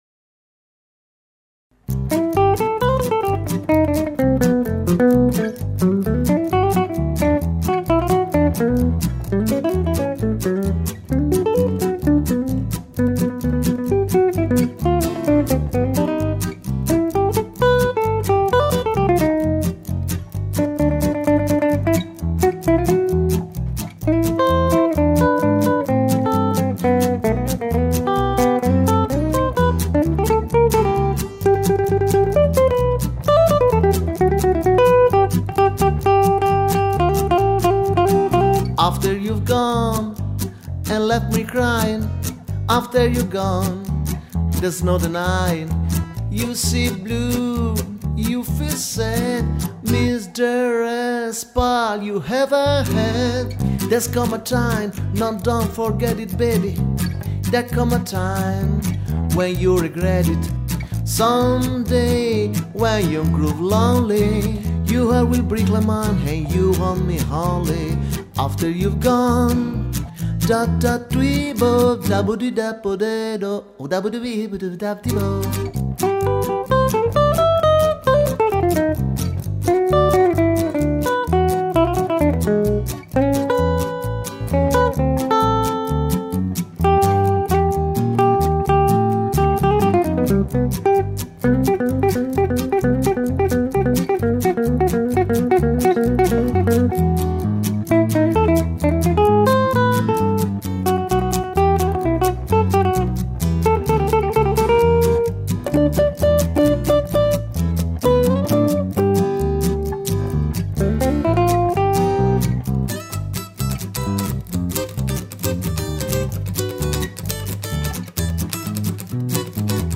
Chitarre varie, basso e voce